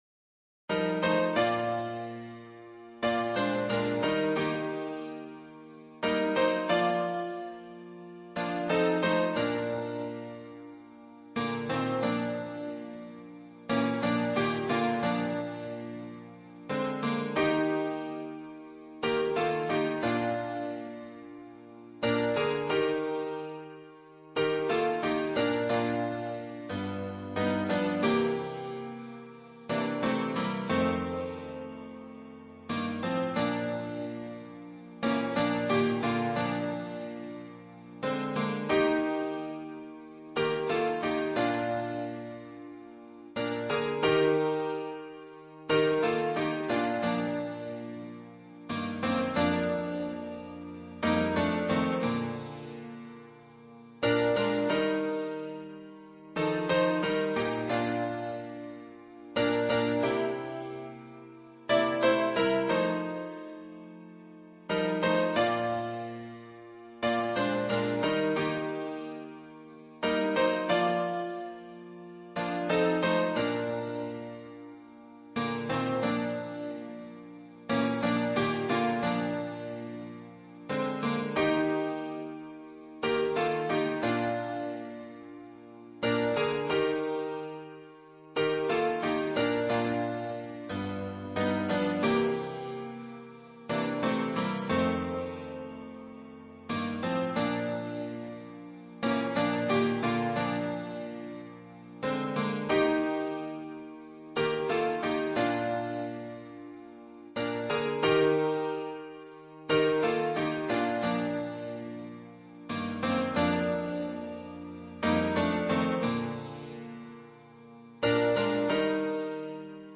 导读：本颂赞诗歌歌谱采用2017年修订版，录音示范暂用旧版，将逐渐更新。
伴奏